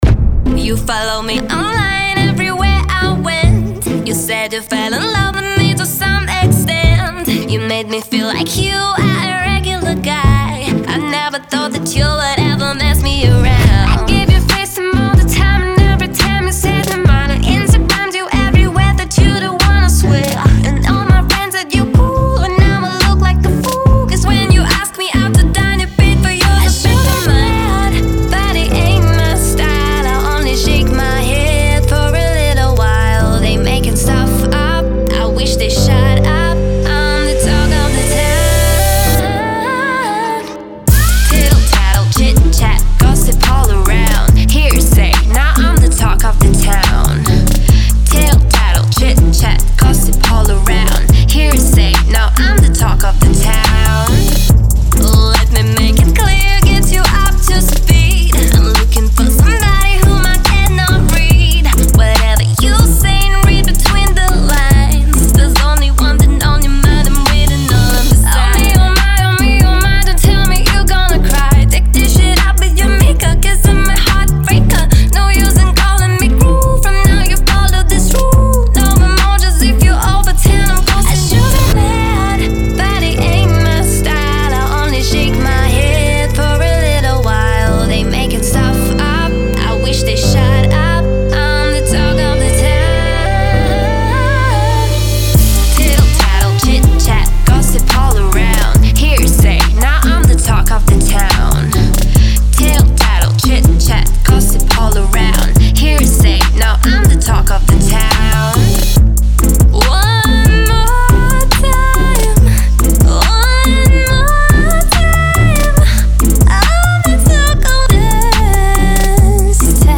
Style: Pop, EDM, Mid-tempo